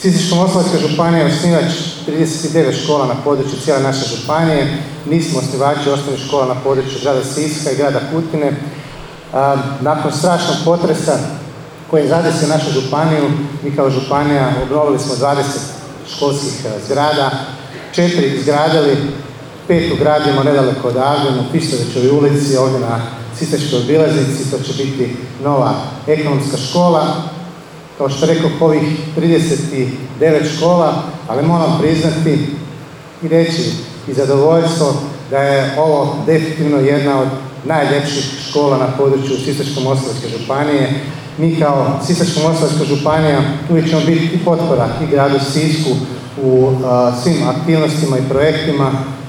Učenicima, djelatnicima škole i svima uključenima u izgradnju ovog kompleksa čestitao je zamjenik župana Mihael Jurić, istaknuvši važnost kontinuiranog ulaganja u obrazovanje.